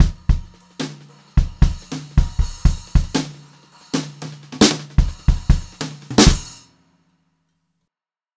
152H2FILL3-R.wav